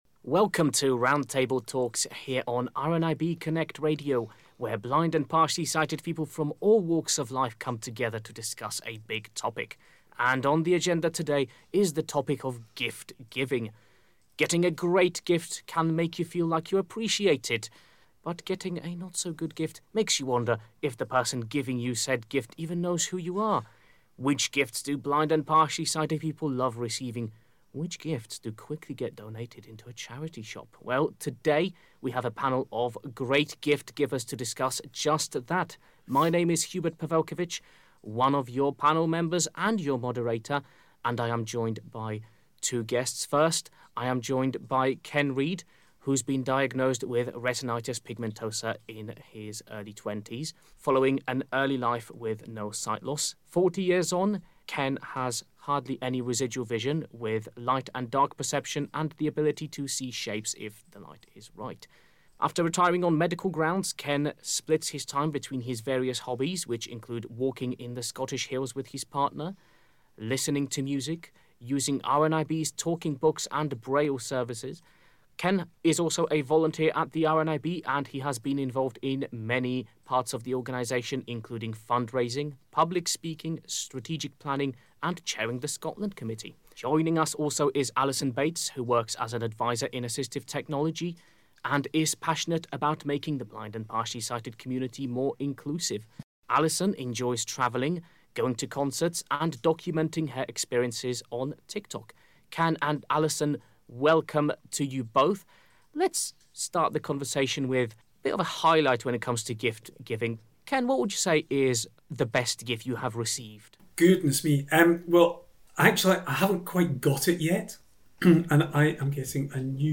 Gift-Giving - Roundtable